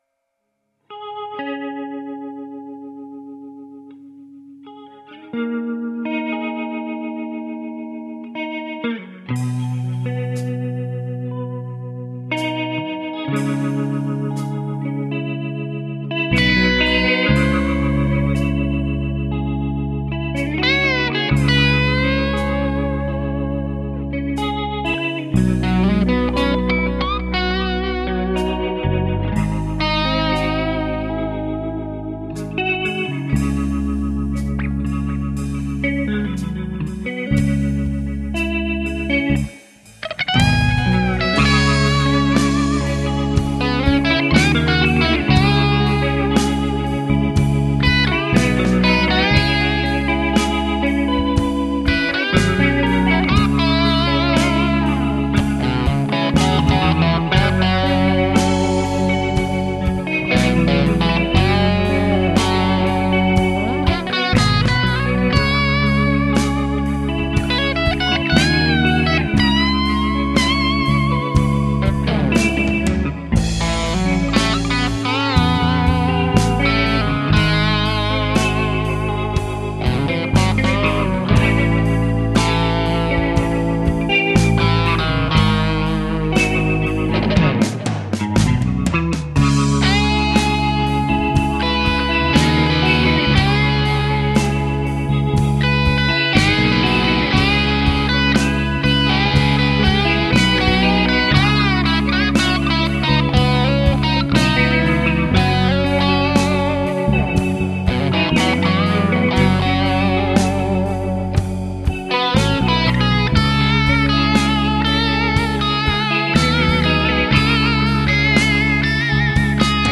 Je n'ai pas résisté à l'envie de me replonger dans l'ambiance floydienne...
J'ai fait ça dans l'urgence, par manque de temps, avec ma strat plus et guitar rig.C'est pour ainsi dire une impro, que j'ai essayé d'aborder dans l'esprit.
J'aime beaucoup, le lâchage des boeufs vers 3'20 est très rock.
Je veux dire par là qu'avec tous ces petits vibratos, harmoniques bien maîtrisées, ces bends, les notes même maintenues ne sont jamais ennuyeuses et tout ceci est finalement très ... technique.